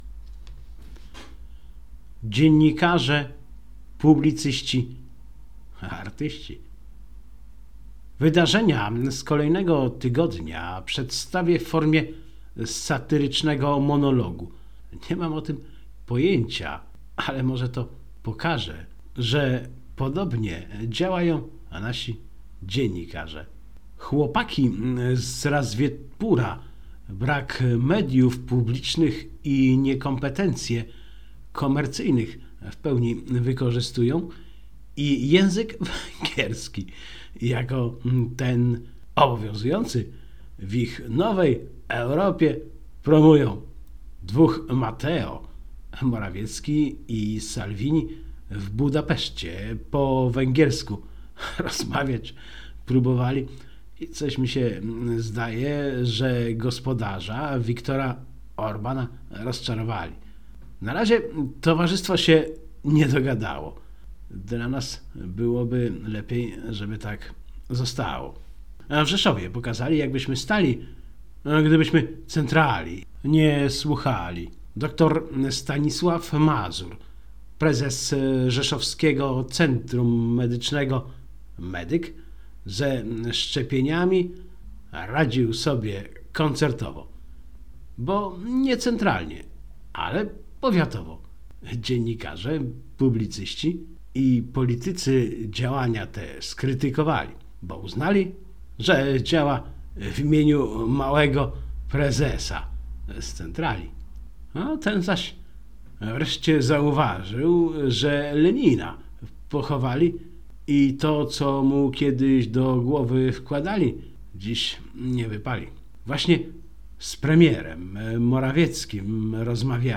Wydarzenia z kolejnego tygodnia przedstawię w formie satyrycznego monologu.